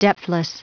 Prononciation du mot depthless en anglais (fichier audio)
Prononciation du mot : depthless